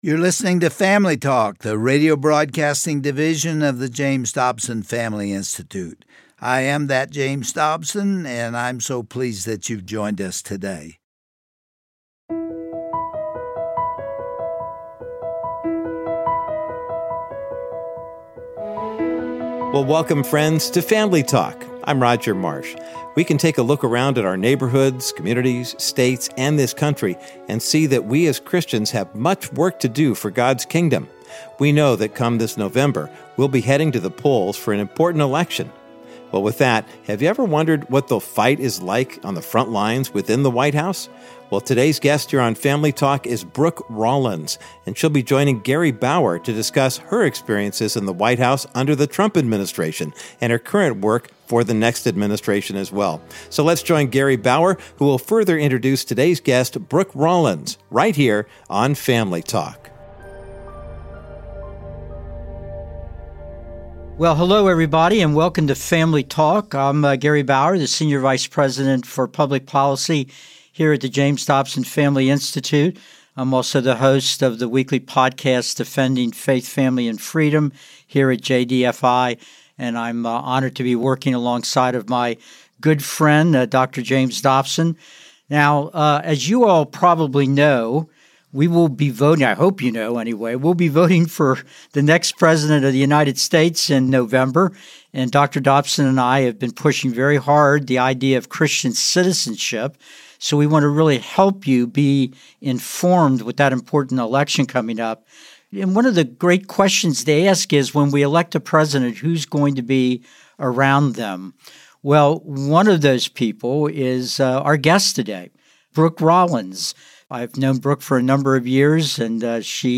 On today’s edition of Family Talk, Gary Bauer interviews Brooke Rollins, the president and CEO of America First Policy Institute. Brooke shares her organization’s mission and blueprint to advance biblically-based, pro-family values in our country with the upcoming presidential election and beyond.